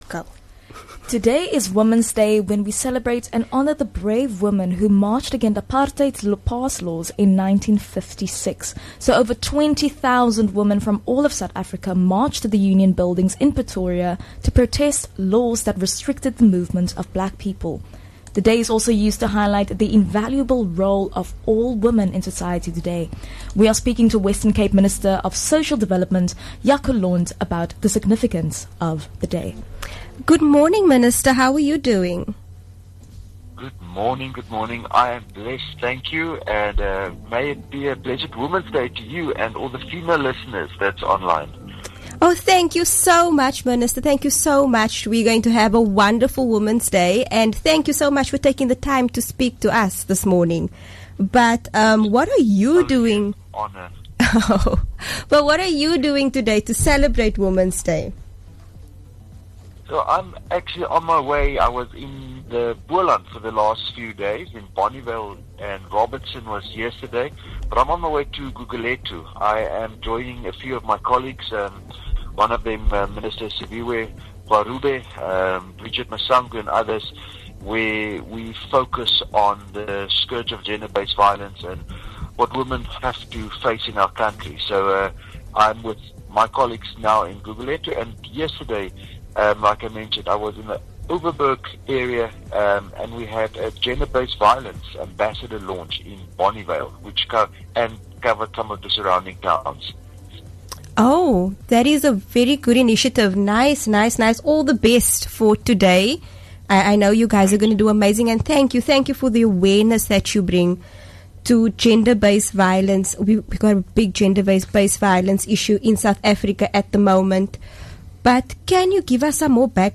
9 Aug WCG Interview - Jaco Londt, Minister of The Western Cape Government.